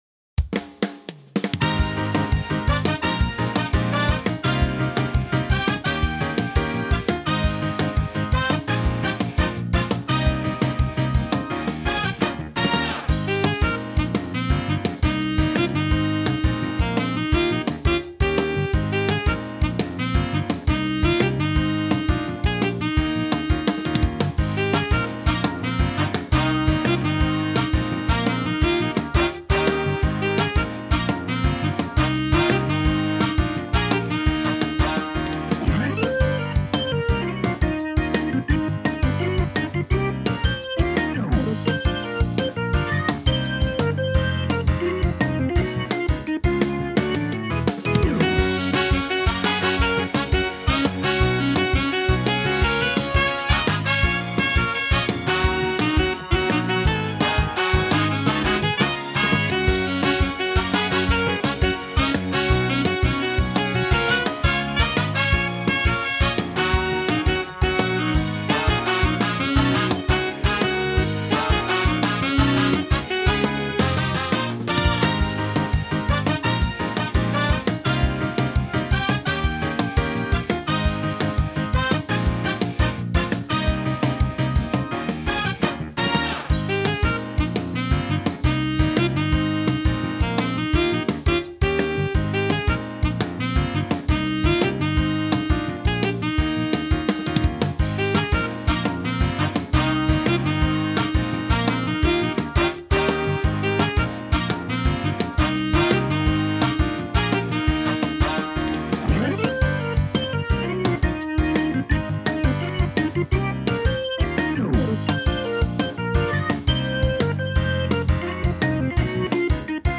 フリーBGM